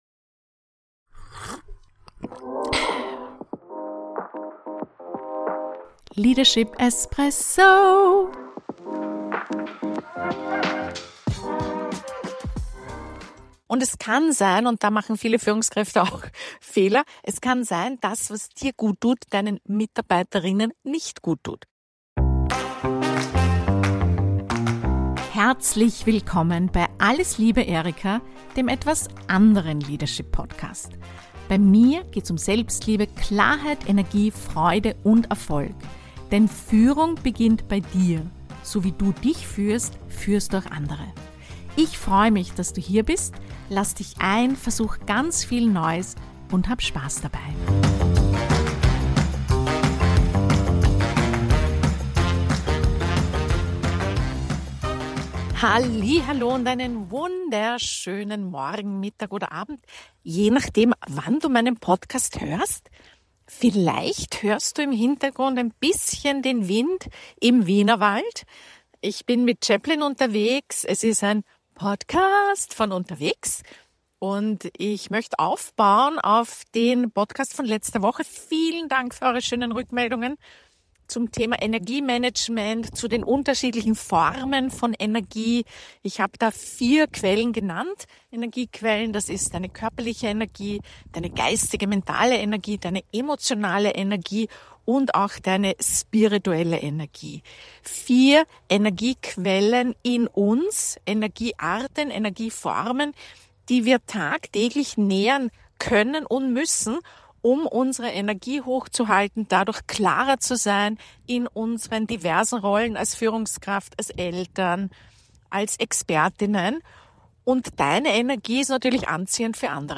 In dieser besonderen „Leadership-Espresso“-Folge nehme ich dich mit in den Wienerwald – live unterwegs mit meinem Hund Chaplin.